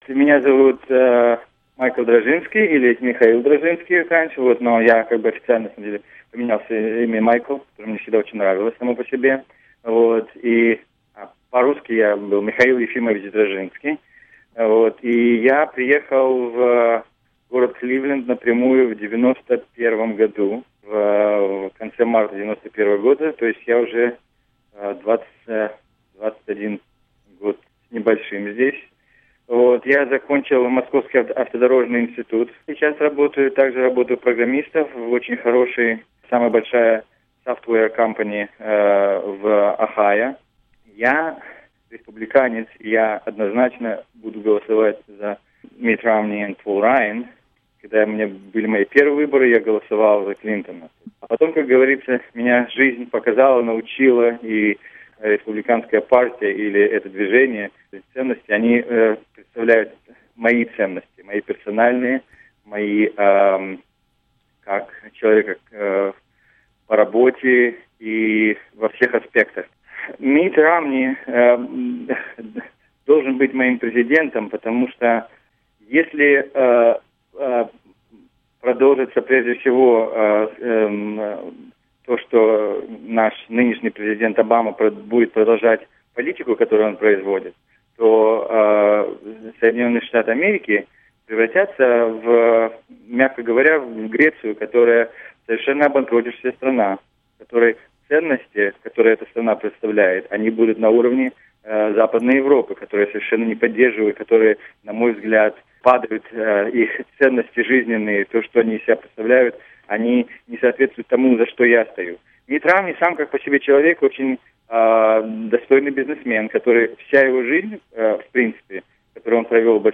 «Голос Америки» побеседовал с двумя русскоязычными американцами, проживающими в этом штате, – демократом и республиканцем – и выяснил, за кого они будут голосовать и почему.